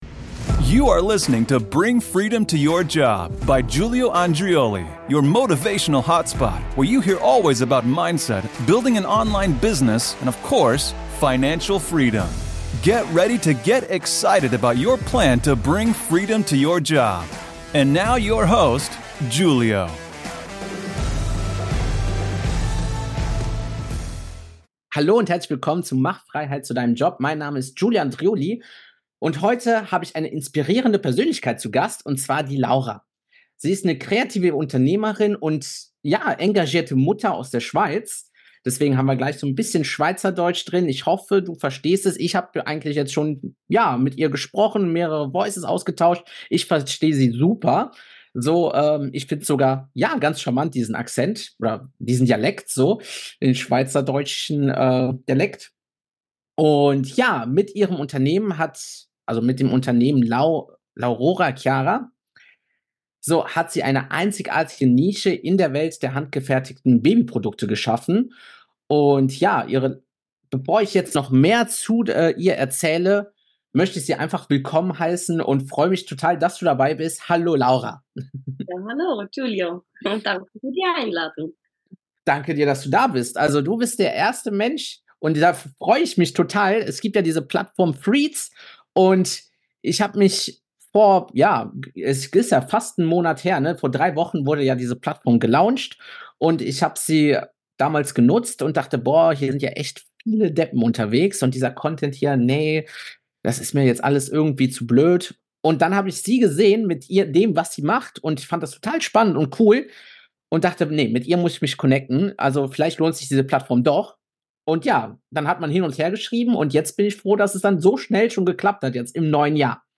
Dieses Interview ist eine Quelle der Inspiration und bietet praktische Tipps für alle Mamas, die ihren Traum vom eigenen Unternehmen verwirklichen möchten.